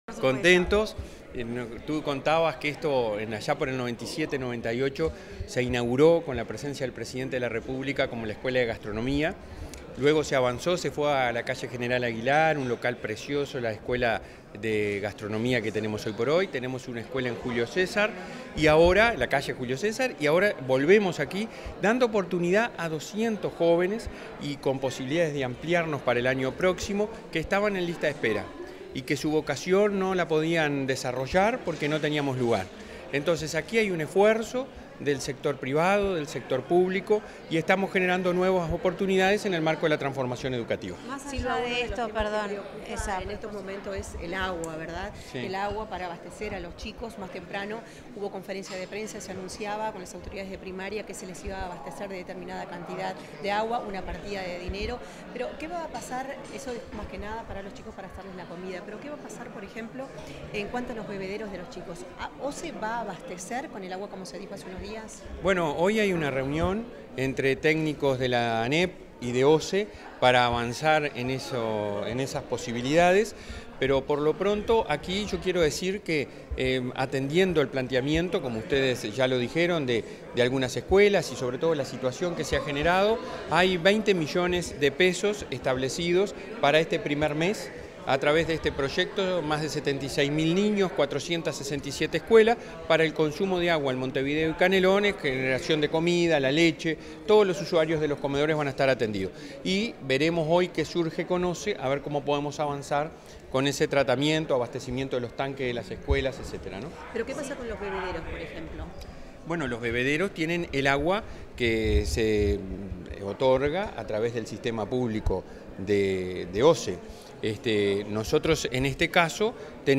Declaraciones del presidente de la ANEP, Robert Silva
Declaraciones del presidente de la ANEP, Robert Silva 15/05/2023 Compartir Facebook X Copiar enlace WhatsApp LinkedIn Tras la inauguración de un anexo de la Escuela de Hotelería de UTU en Montevideo, este 15 de mayo, el presidente del Consejo Directivo Central (Codicen), de la Administración Nacional de Educación Pública (ANEP), Robert Silva, realizó declaraciones a la prensa.